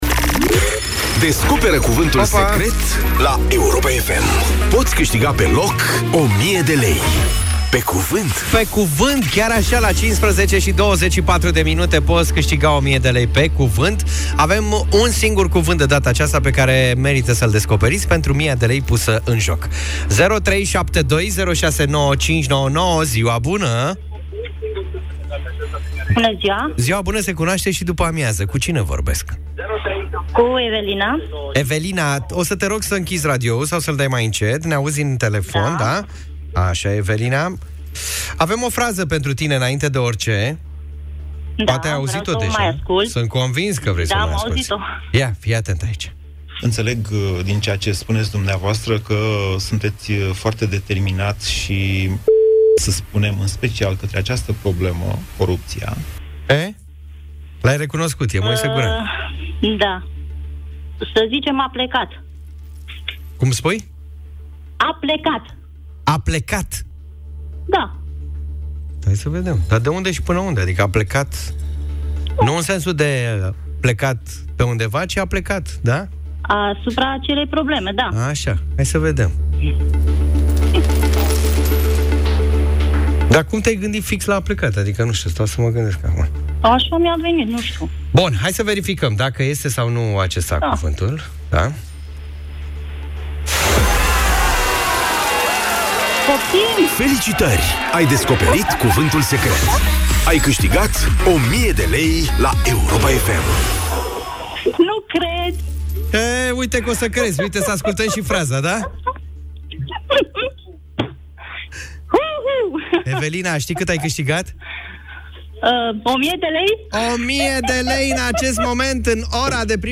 S-a găsit Cuvântul Secret, în direct în Ore de Primăvară!